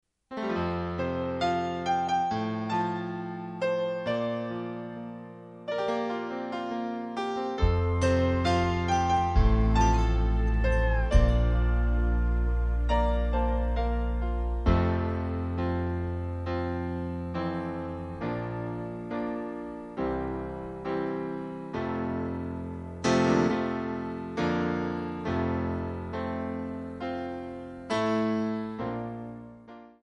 Backing track Karaoke
Country, 1990s